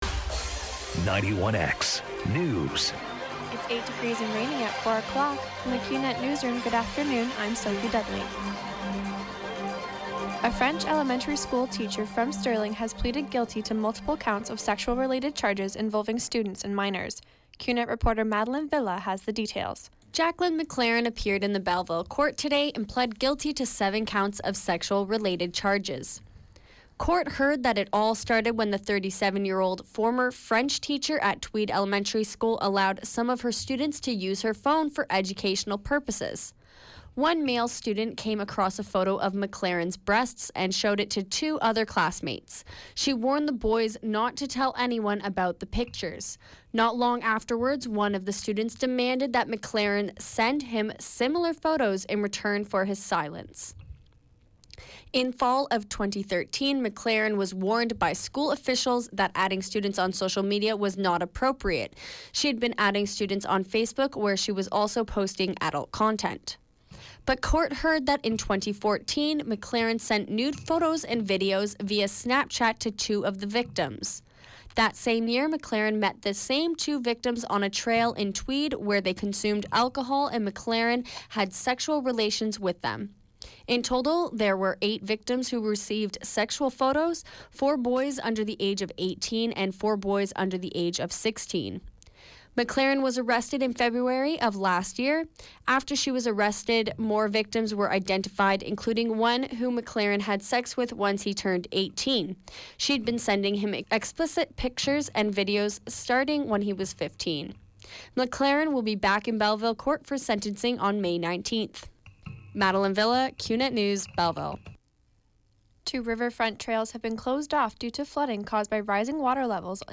91X FM Newscast – Tuesday, March 7, 2017, 4 p.m.